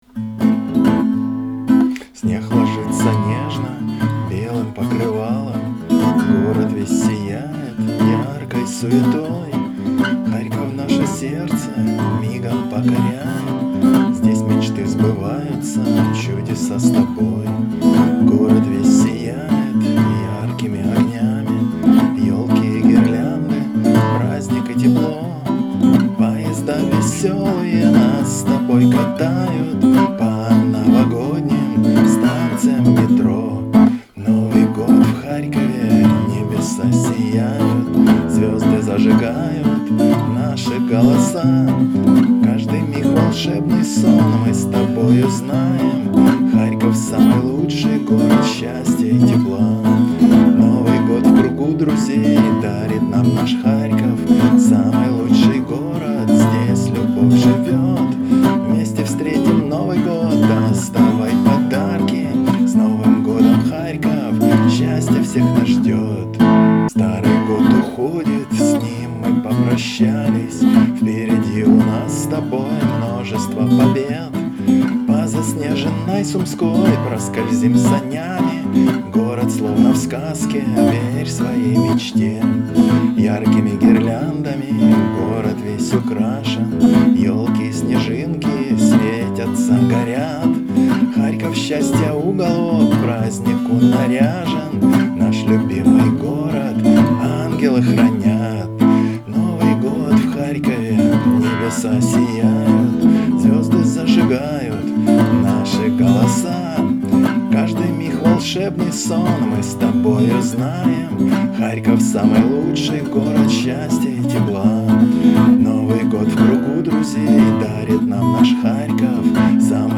(под гитару, дворовая версия)